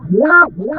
VOX FX 3  -L.wav